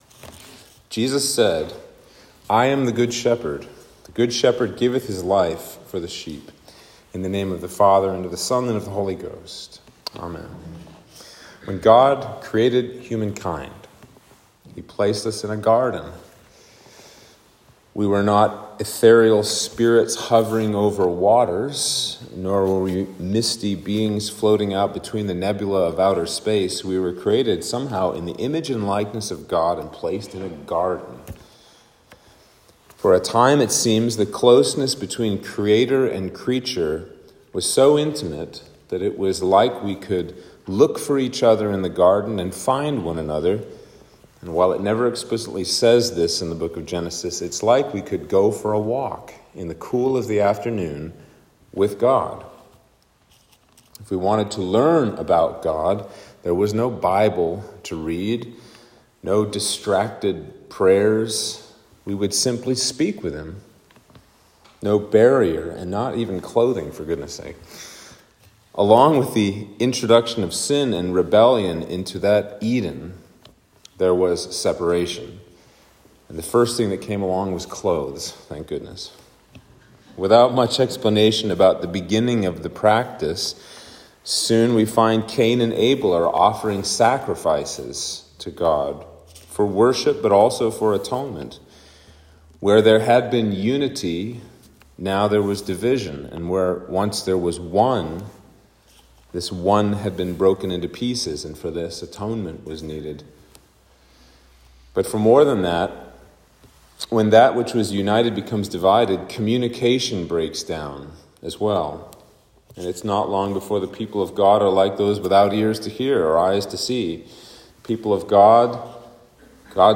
Sermon for Easter 2